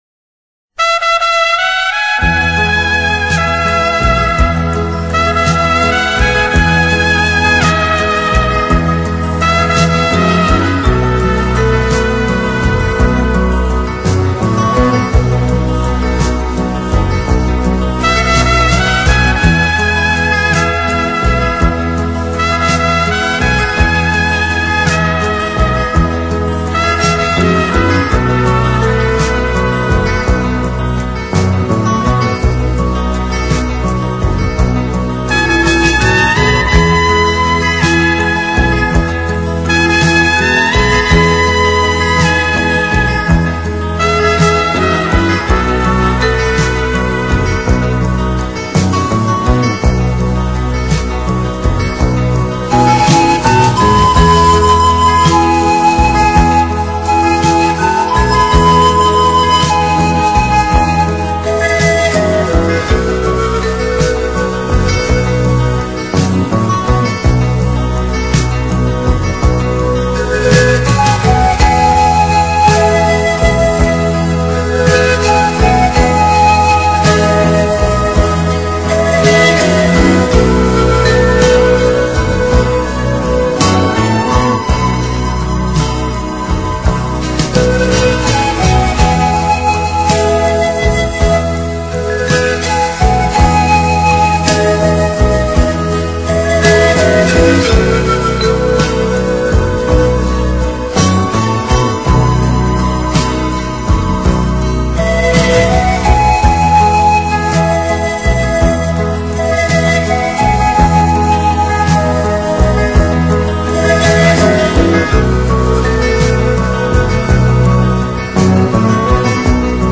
Instrumentais Para Ouvir: Clik na Musica.
Flauta De Pan